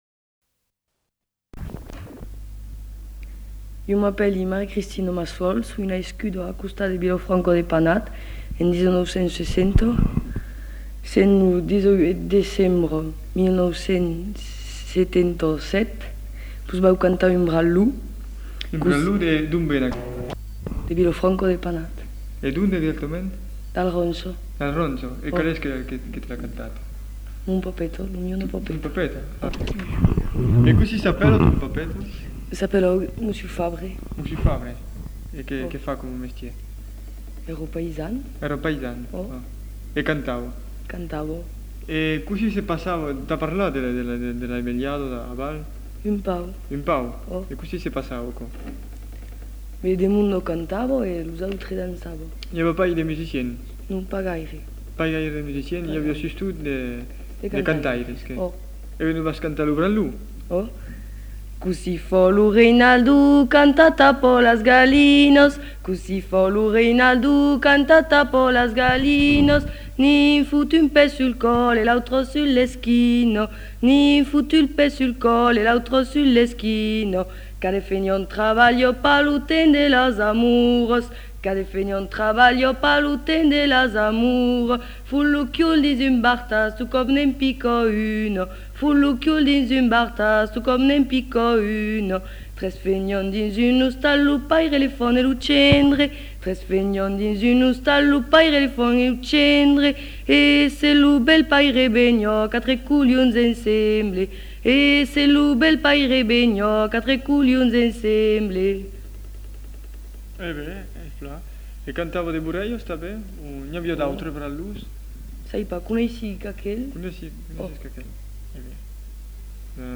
Aire culturelle : Rouergue
Genre : chant
Effectif : 1
Type de voix : voix de femme
Production du son : chanté
Danse : branlon